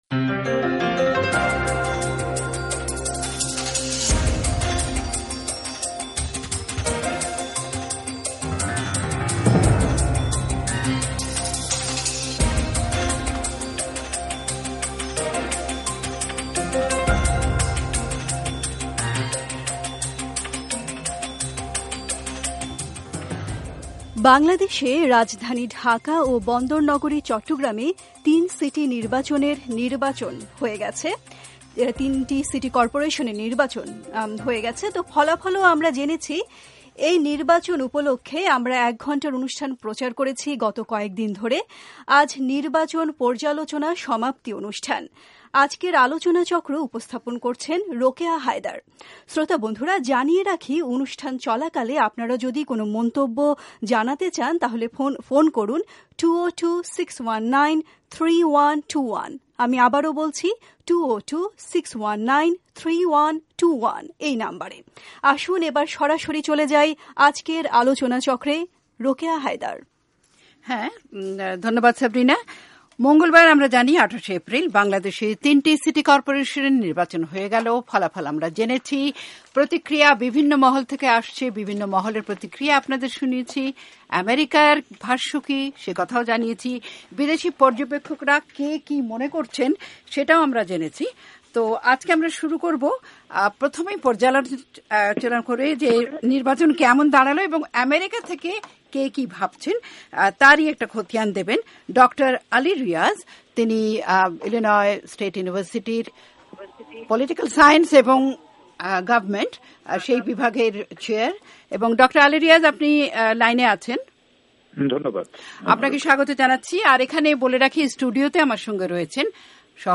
সিটি কর্পোরেশন নির্বাচন নিয়ে আলোচনা চক্র